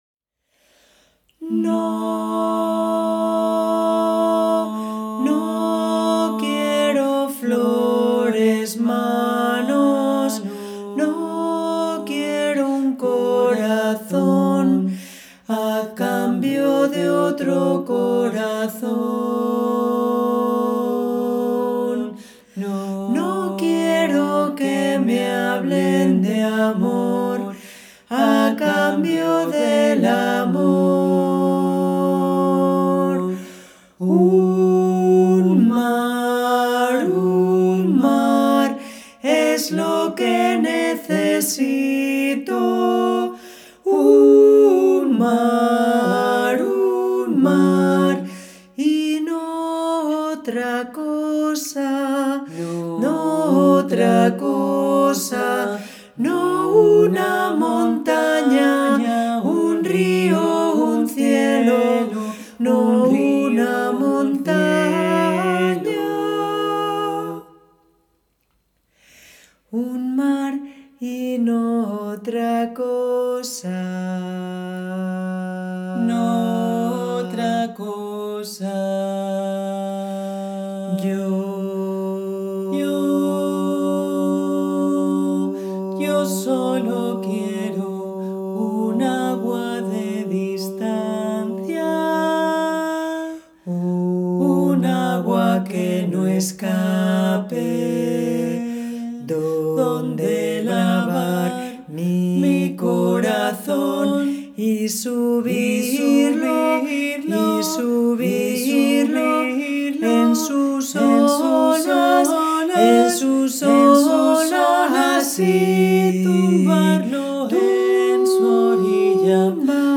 Song for 2 a cappella altos
Folklore